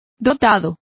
Complete with pronunciation of the translation of gifted.